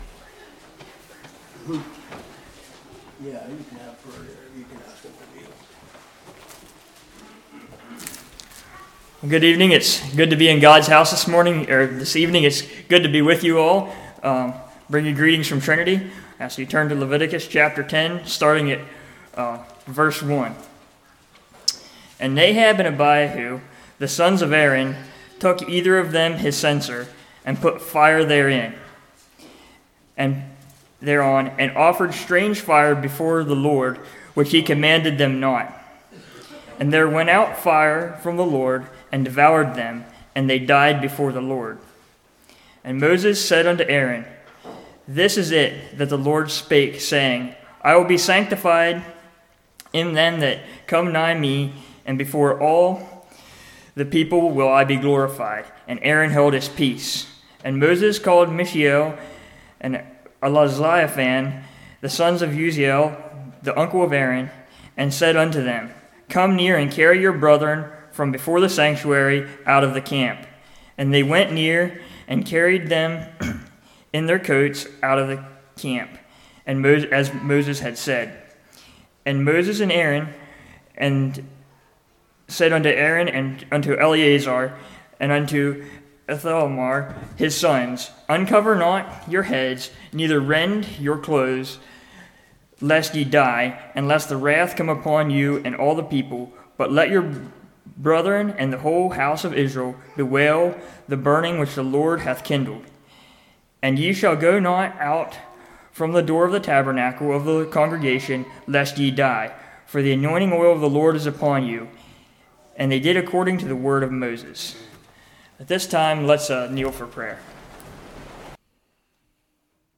Leviticus 10:1-7 Service Type: Revival The Book of Leviticus Teaches us…